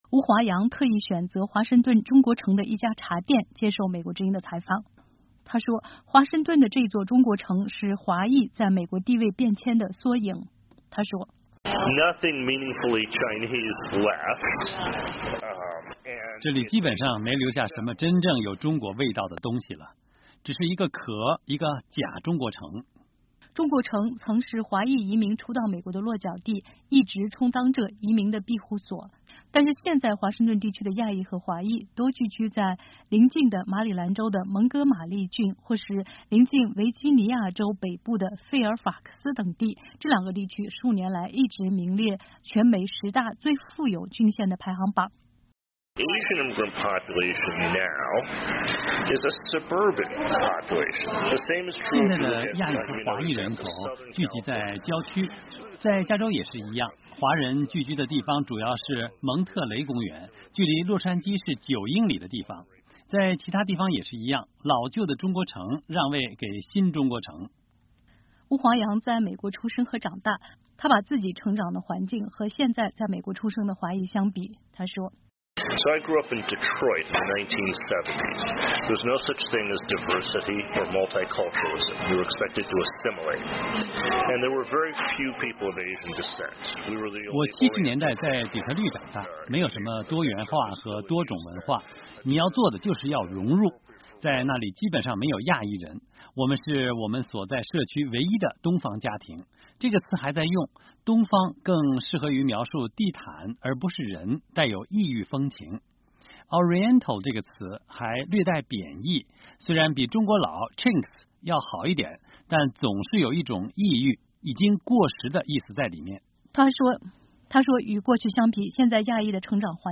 他不久前接受美国之音的专访，谈亚裔、特别是华裔，在美国的地位的变迁。
他特意选择华盛顿“中国城”的一家“茶店”接受美国之音的采访。